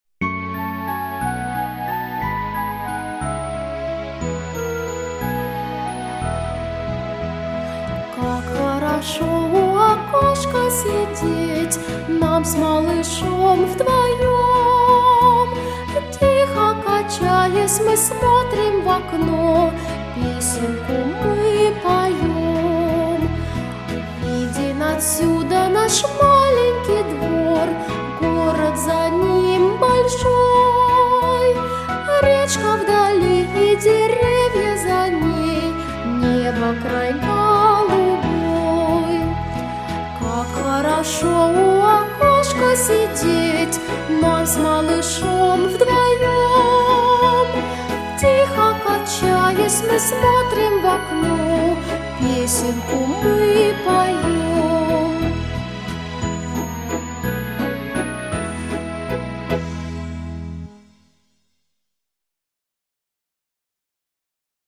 Детский сад